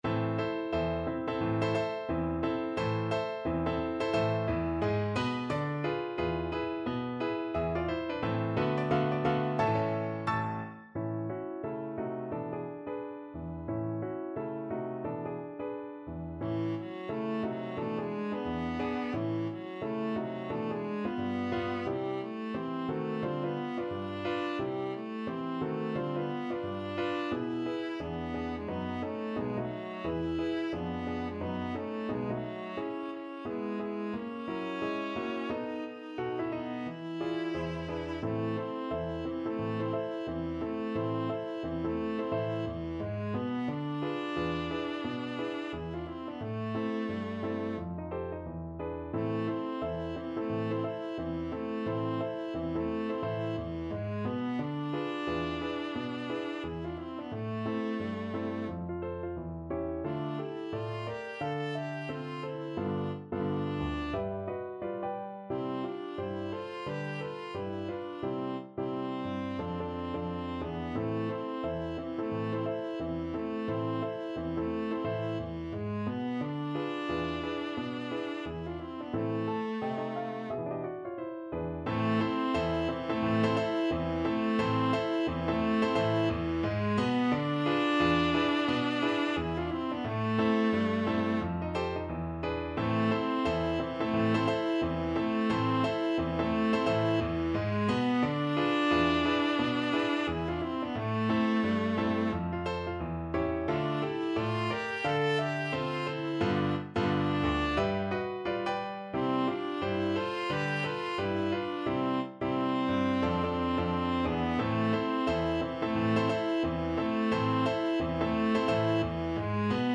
~ = 176 Moderato
2/2 (View more 2/2 Music)
Jazz (View more Jazz Viola Music)
Rock and pop (View more Rock and pop Viola Music)